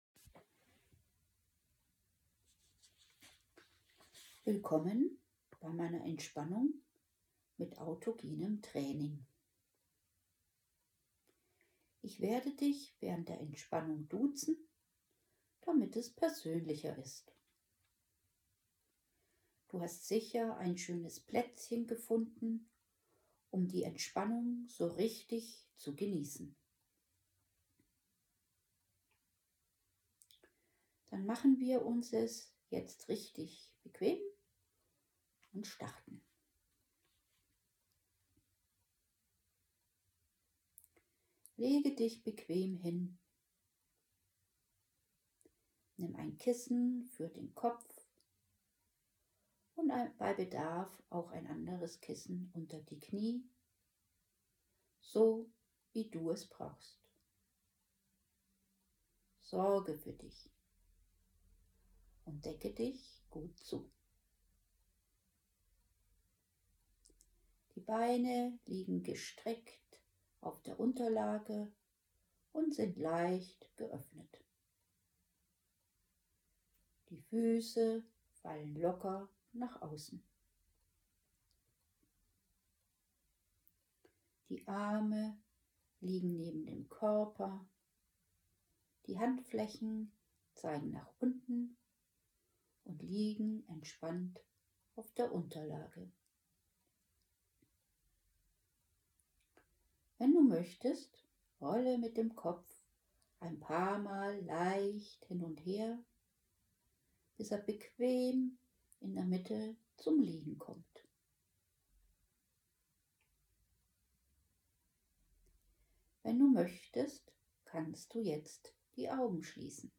Autogenes Training: geführte Entspannungseinheit, wann und wo Sie wollen.
Der folgende Link führt Sie durch eine von mir geführte AT Sitzung: